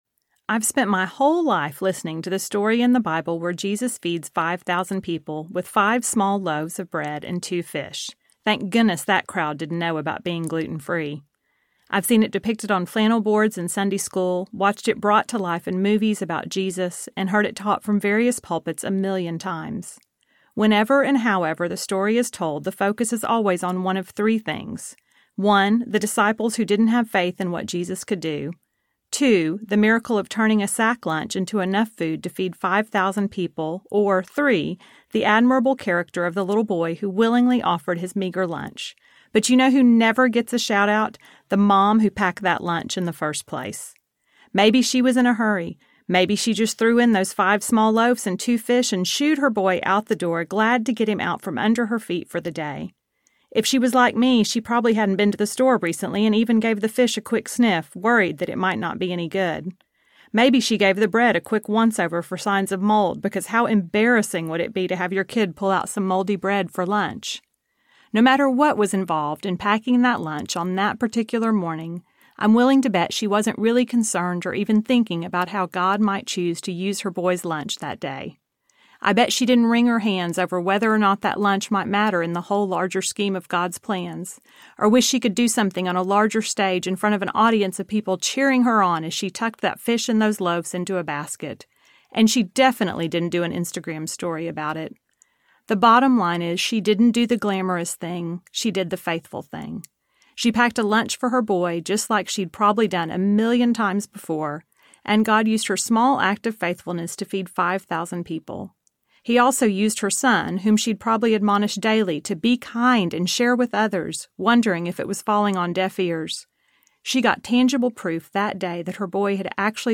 It’s All About the Small Things Audiobook
5.3 Hrs. – Unabridged